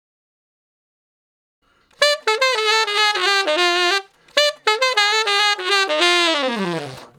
066 Ten Sax Straight (D) 23.wav